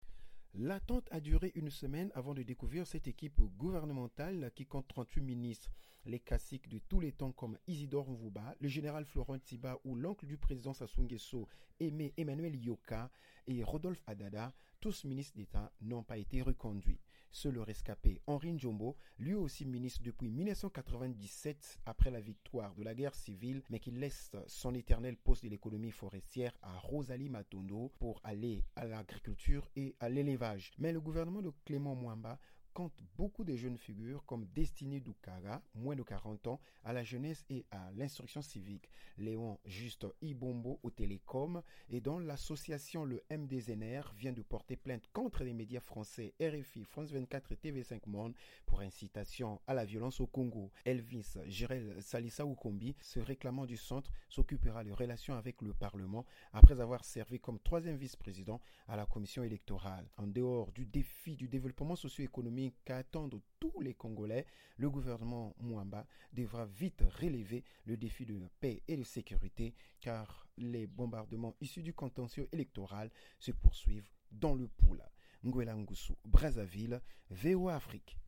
Reportage de Brazzaville sur le nouveau gouvernement